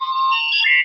Computer6.wav